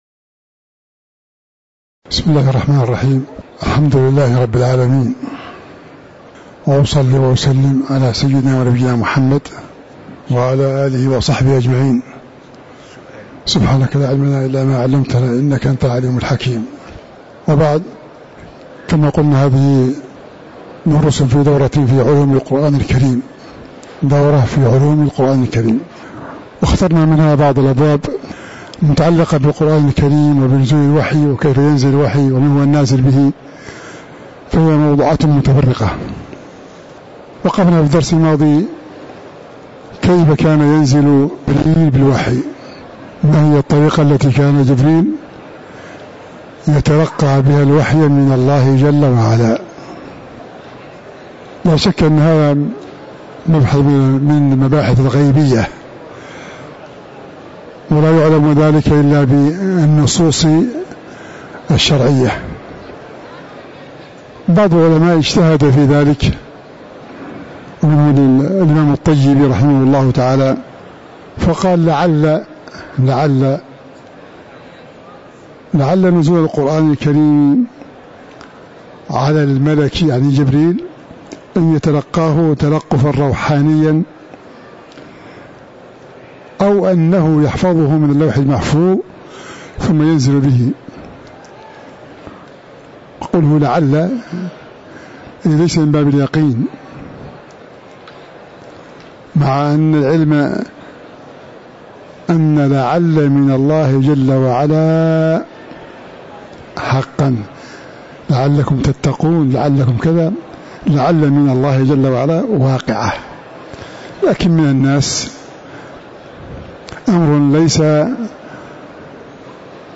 تاريخ النشر ١٨ محرم ١٤٤٥ هـ المكان: المسجد النبوي الشيخ